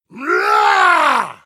roar_01
Category: Games   Right: Personal